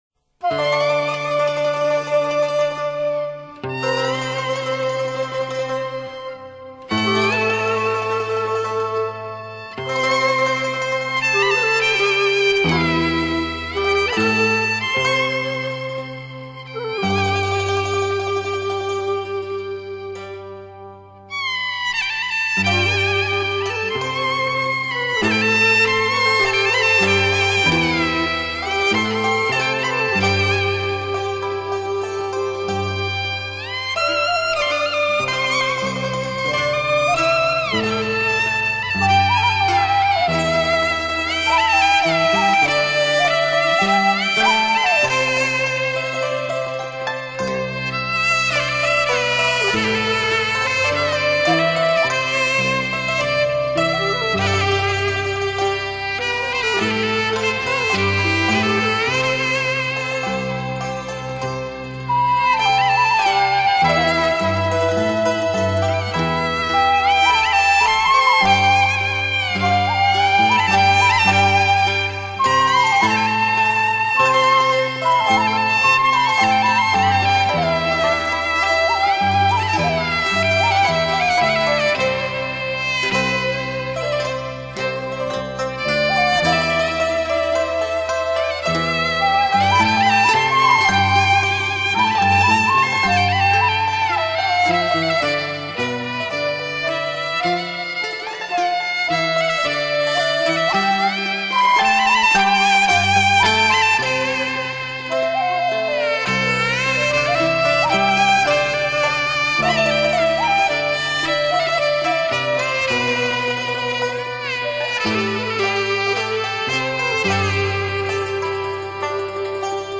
喉管/唢呐/箫
琵琶
扬琴
高胡/二弦
阮/击乐
高胡領奏